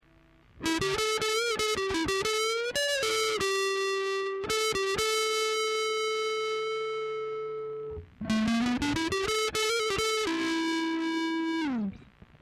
i recorded samples from my pedals to the point where its doin the "noise" and not necesserely to the max drive setting. the fender amp's EQ is set flat and recorded line coz couldnt with a mic(night time here). quality of the line is crap but still u can get the meaning. also i think it sounds lots more disturbing when hearing it directly from my amp. the speakers make it show more.
i used the neck(single coil) to record the samples.